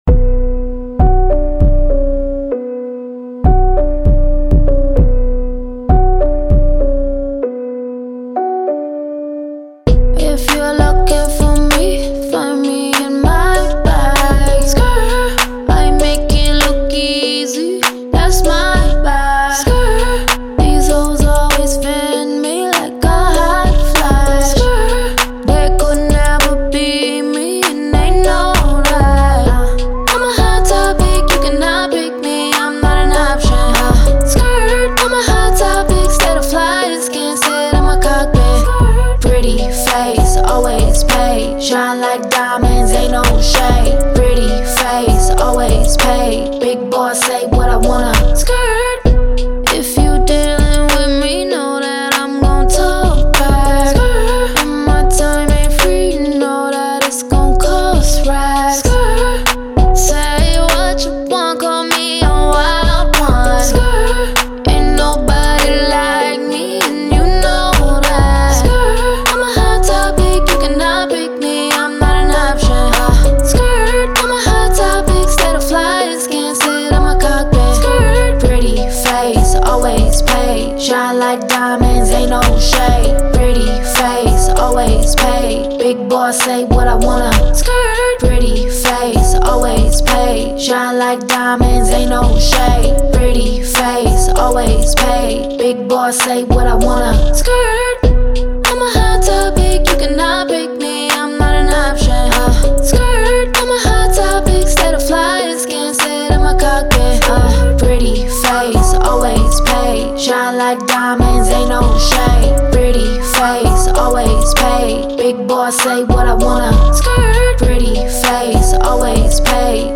Hip Hop
B Min